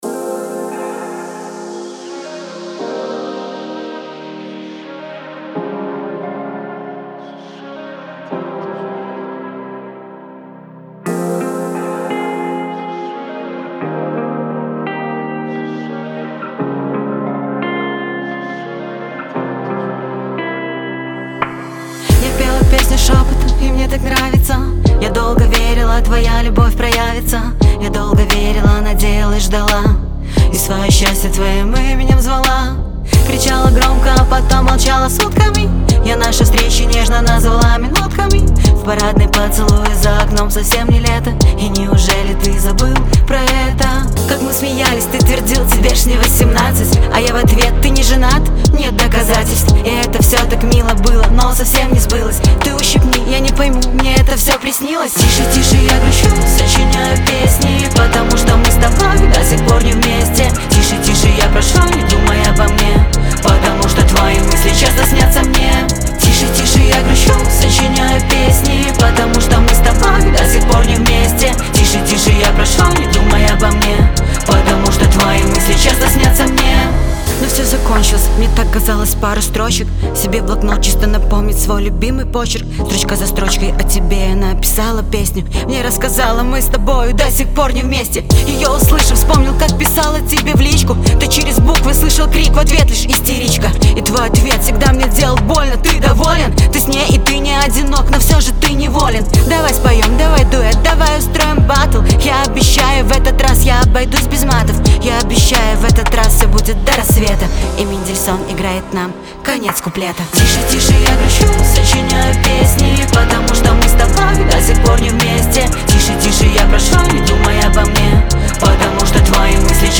мелодичная поп-песня